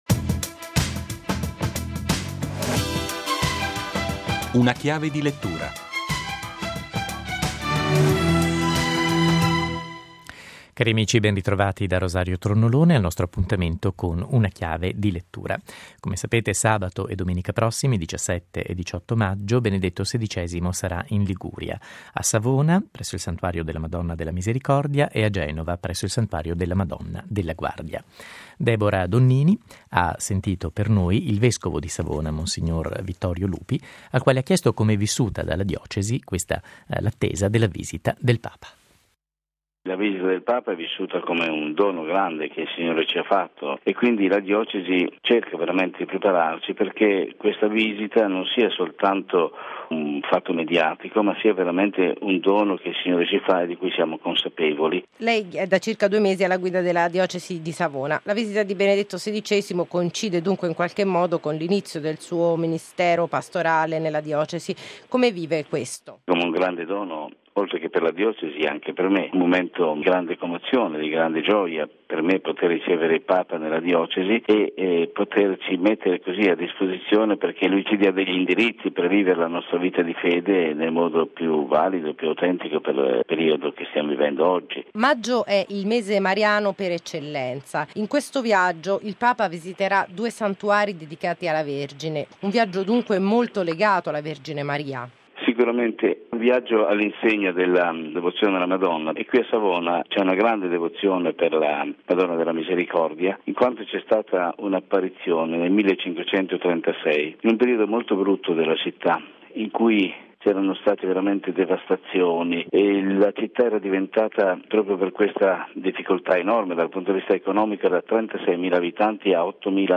Con noi mons. Vittorio Lupi, vescovo di Savona-Noli e il card. Angelo Bagnasco.